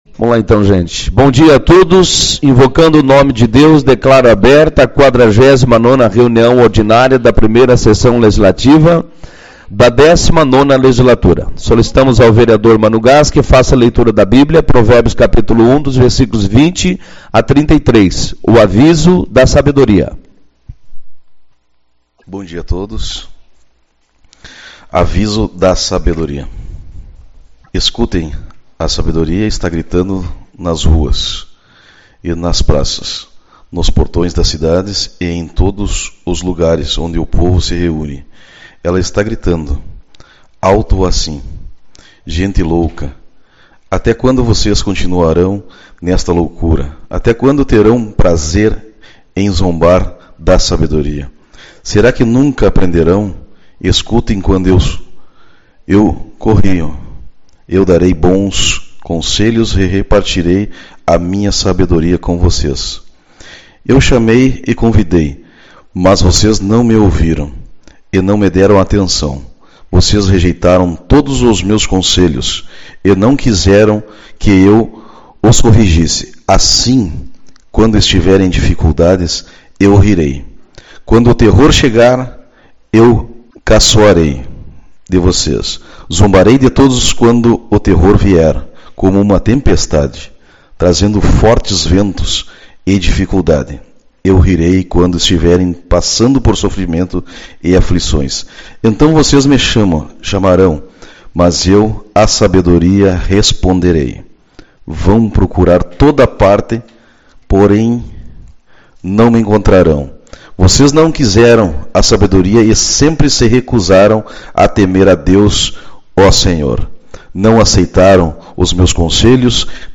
14/08 - Reunião Ordinária